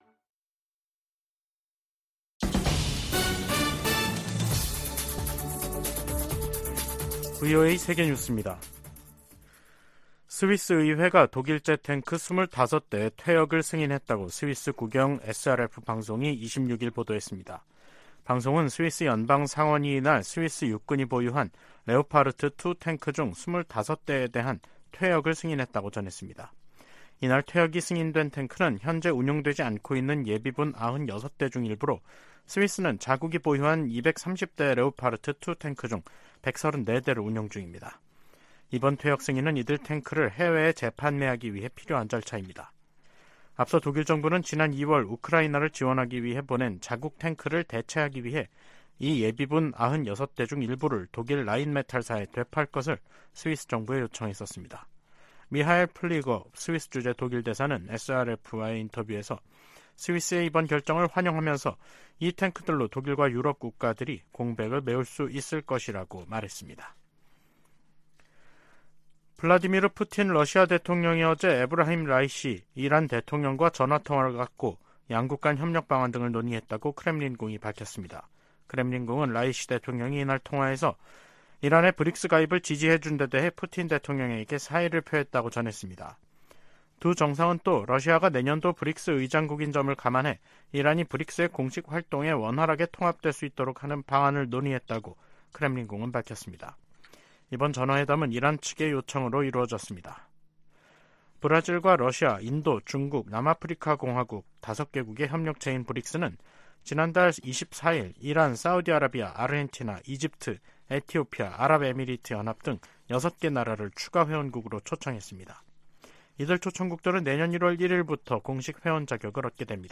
VOA 한국어 간판 뉴스 프로그램 '뉴스 투데이', 2023년 9월 27일 3부 방송입니다. 미 국무부는 미한 연합훈련을 '침략적 성격이 강한 위협'이라고 규정한 북한 유엔대사의 발언에 이 훈련은 관례적이고 방어적인 것이라고 반박했습니다. 한국의 신원식 국방부 장관 후보자는 9.19 남북 군사합의 효력을 최대한 빨리 정지하도록 추진하겠다고 밝혔습니다. 미국과 한국, 일본 등은 제 54차 유엔 인권이사회에서 북한의 심각한 인권 유린 실태를 강력하게 규탄했습니다.